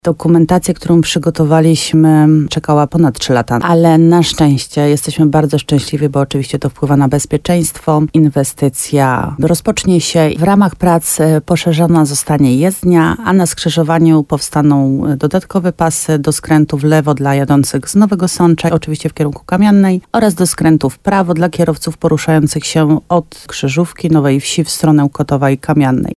Jak informowała w programie Słowo za Słowo na antenie RDN Nowy Sącz Marta Słaby, wójt gminy Łabowa, drogą po przebudowie powinniśmy jeździć jeszcze przed zimą.